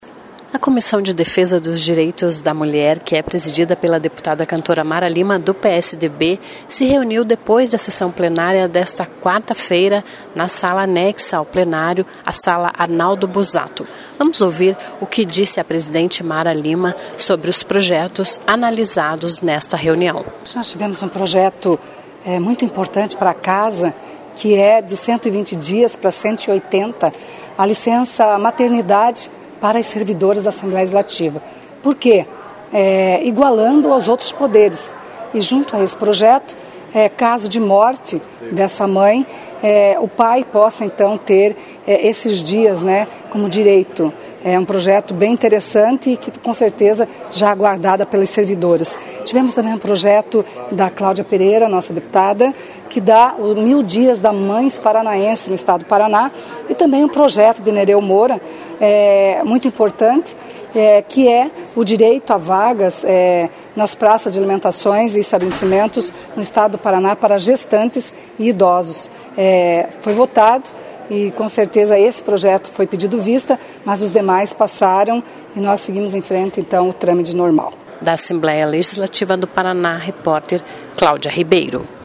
Foram três os projetos analisados pela Comissão de Defesa dos Direitois da Mulher da Casa, que é presidida pela deputada Cantora Mara Lima (PSDB). Vamos ouvir o que ela diz sobre as propostas votadas.